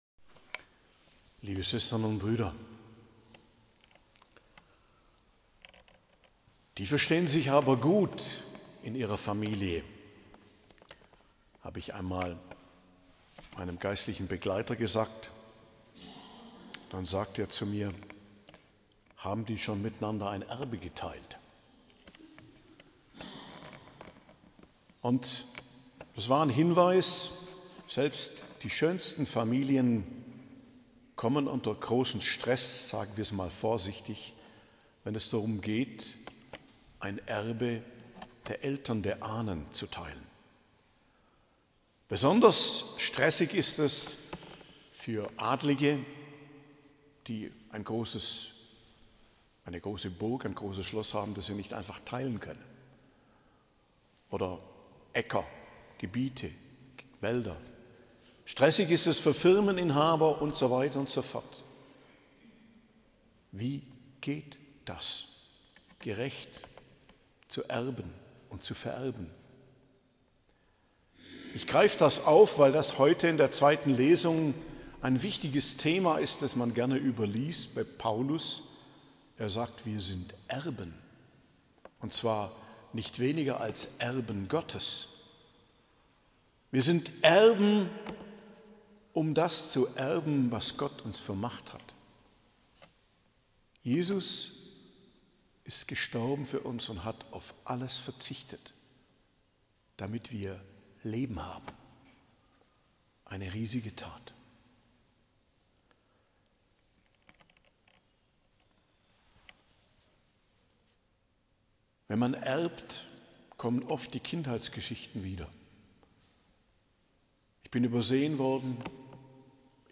Predigt am Pfingstmontag, 9.06.2025 ~ Geistliches Zentrum Kloster Heiligkreuztal Podcast